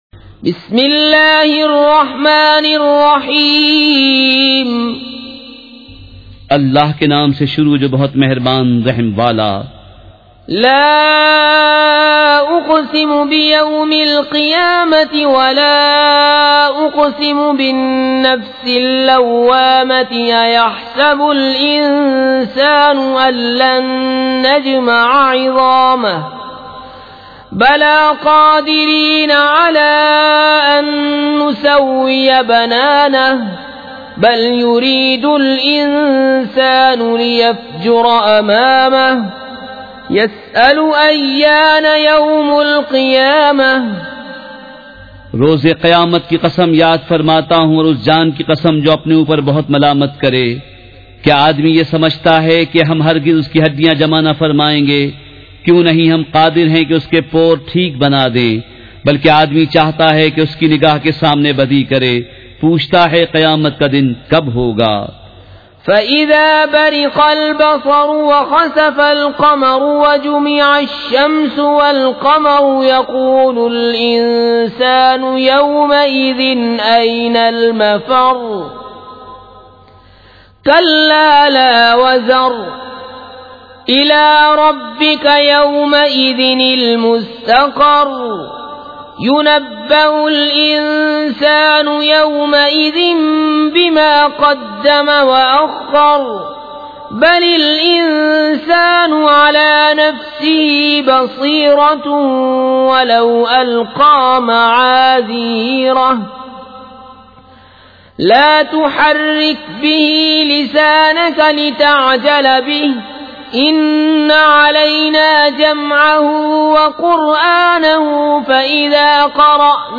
سورۃ القیامۃ مع ترجمہ کنزالایمان ZiaeTaiba Audio میڈیا کی معلومات نام سورۃ القیامۃ مع ترجمہ کنزالایمان موضوع تلاوت آواز دیگر زبان عربی کل نتائج 2136 قسم آڈیو ڈاؤن لوڈ MP 3 ڈاؤن لوڈ MP 4 متعلقہ تجویزوآراء